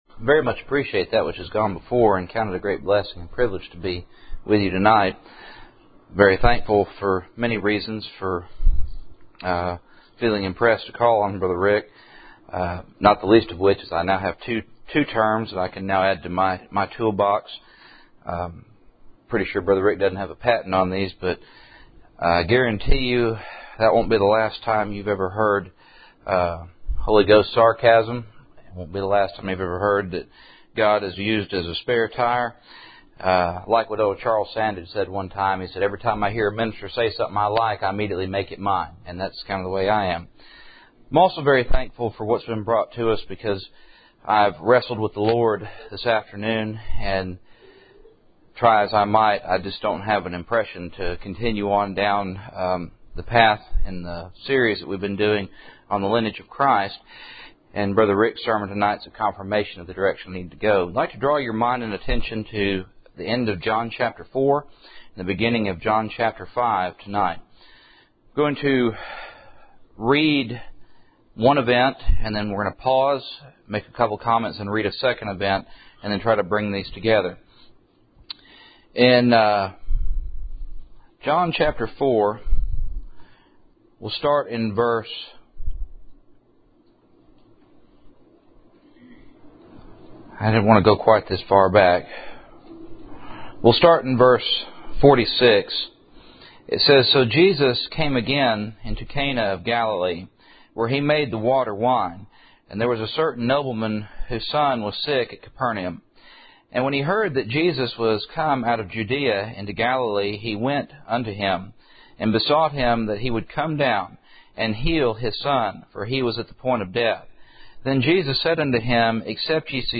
Cool Springs PBC Sunday Evening %todo_render% « The Choosing of Jacob